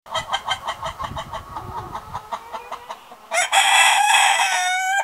Clucking Chicken Sound Button - Free Download & Play